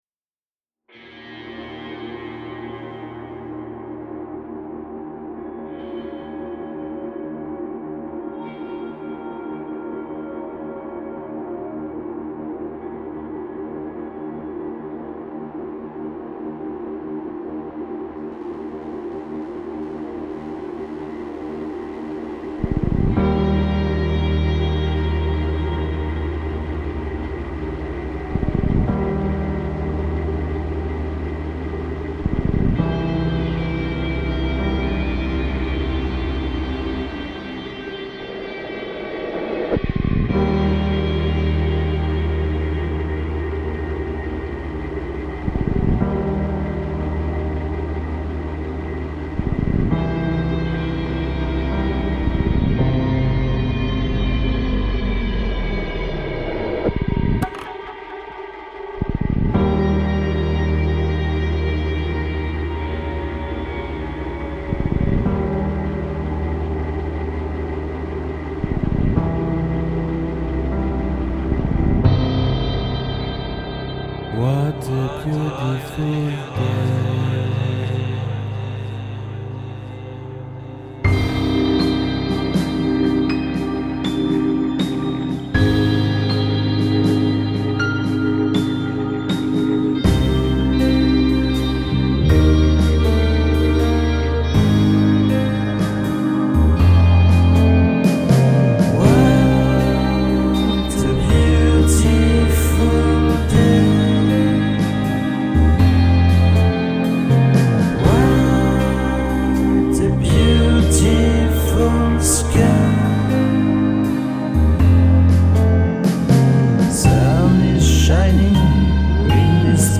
échange à distance des fichiers sons